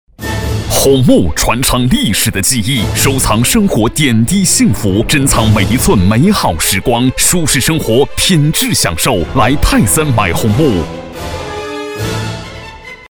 广告配音
轻松 浑厚